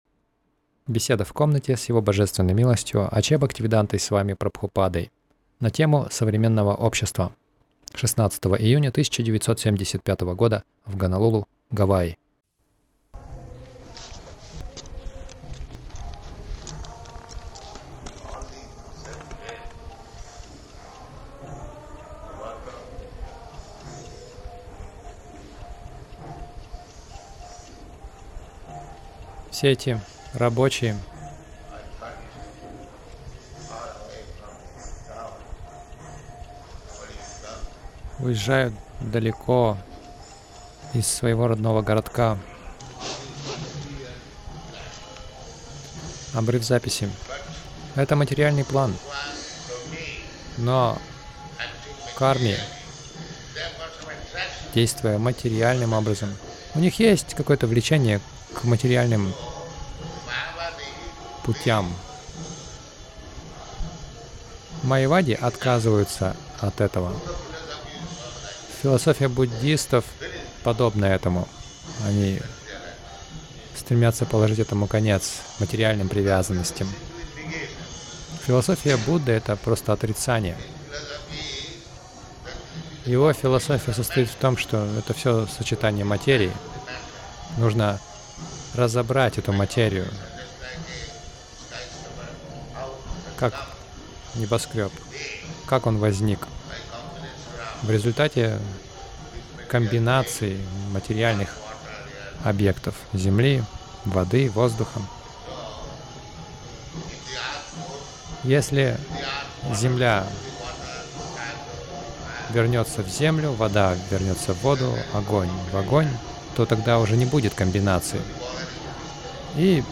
Беседа — Как Запад развращает Восток
Милость Прабхупады Аудиолекции и книги 16.06.1975 Беседы | Гонолулу Беседа — Как Запад развращает Восток Загрузка...